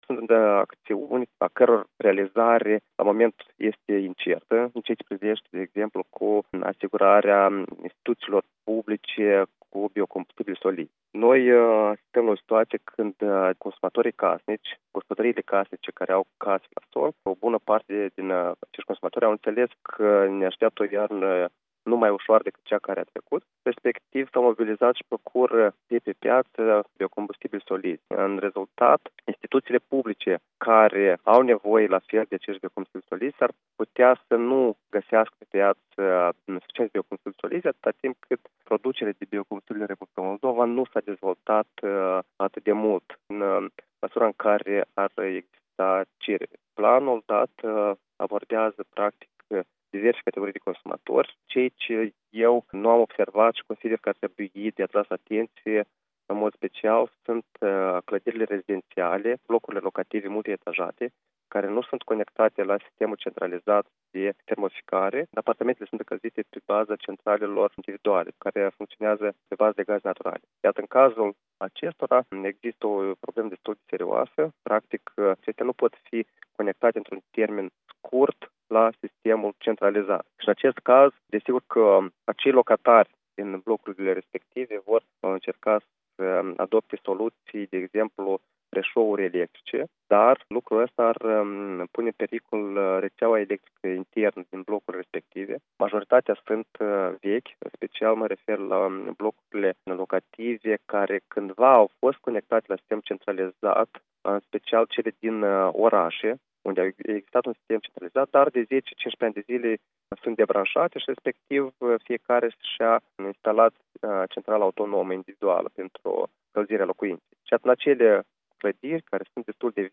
Interviu cu expertul în energetică